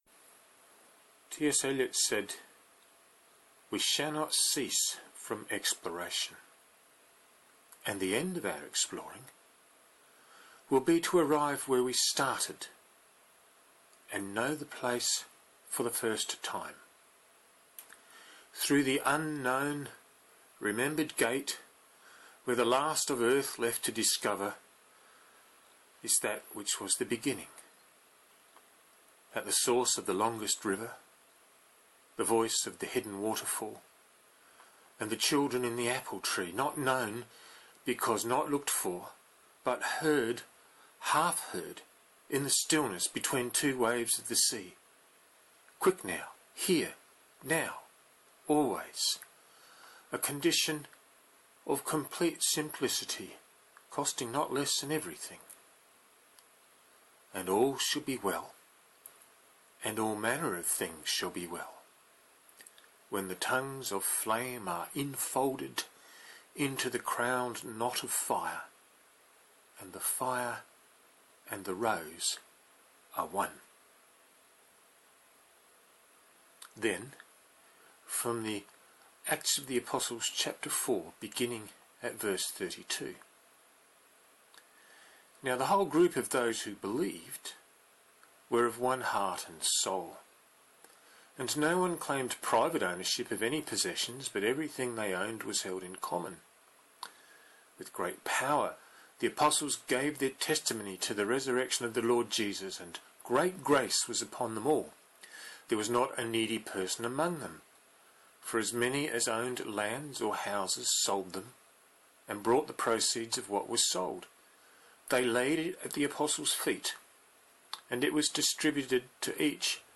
You can listen to the readings, and the sermon draft here. (12 minutes)